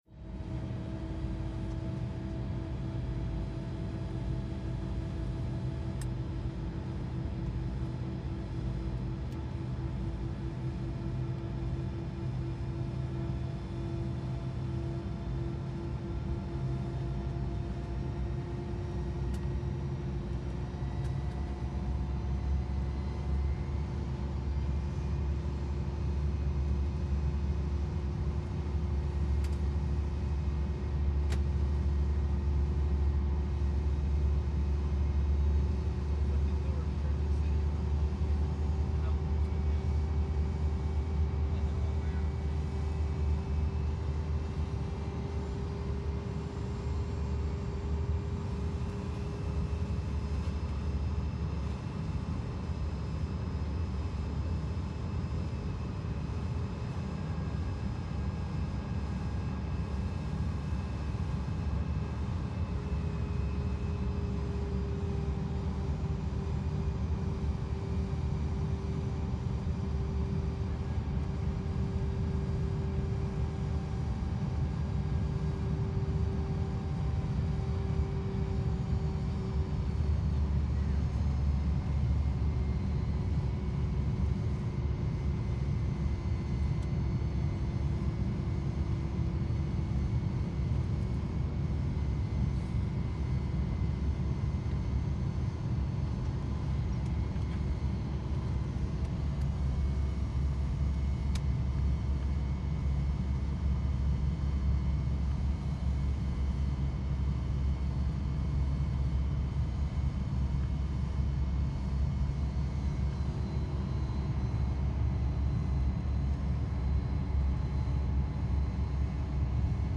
Boeing 727, interior, making approach, varying engine pitch